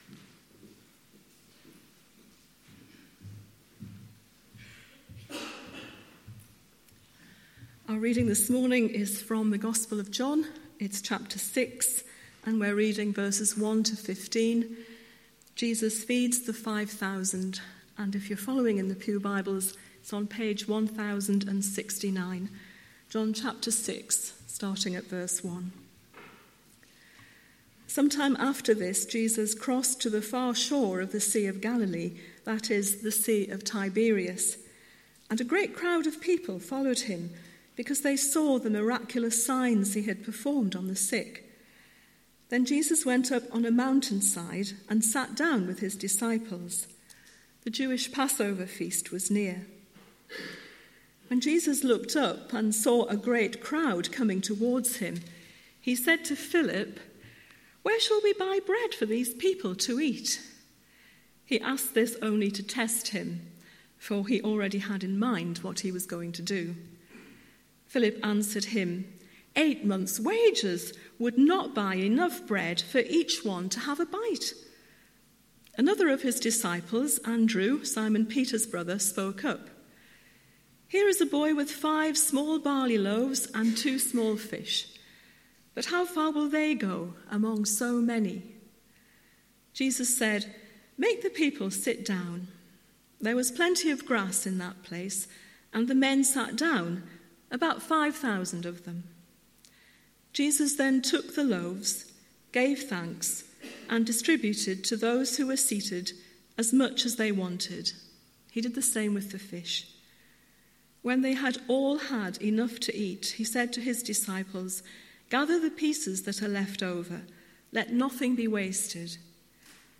Morning Service , Guest Speaker